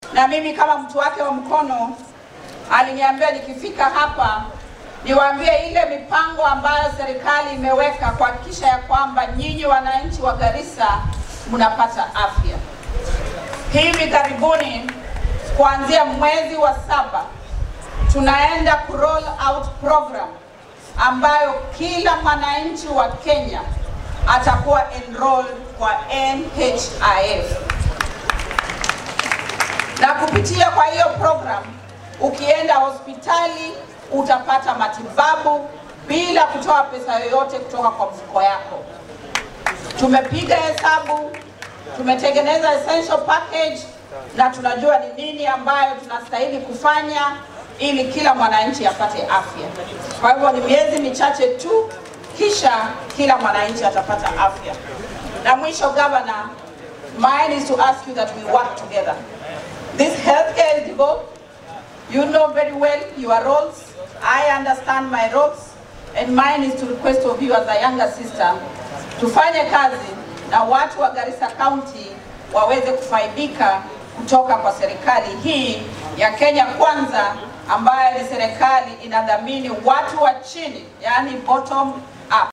Hadalkan ayey jeedisay xili ay ka qeyb galaysay munasabada maalinta xanuunka kansarka oo meelaha looga dabal deegay ay ka mid ahayd magalaada Garissa.